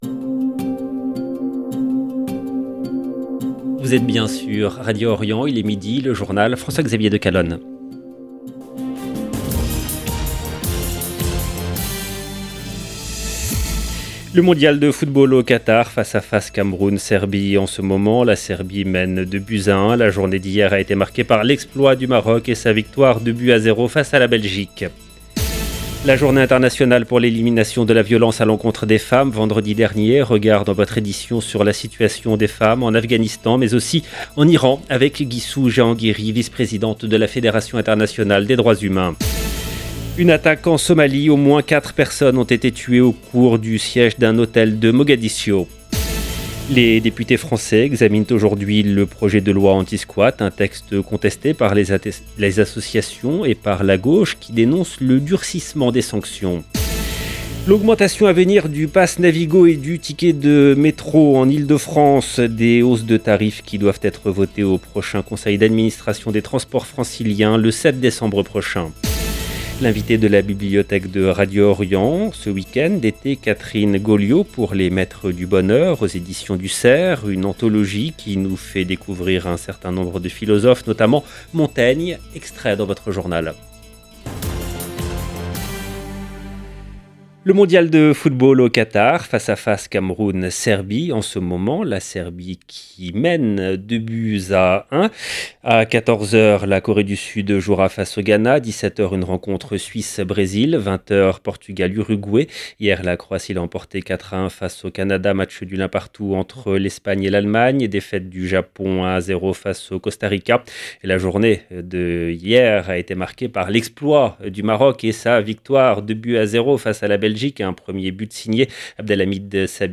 LE JOURNAL EN LANGUE FRANCAISE DE MIDI DU 28/11/22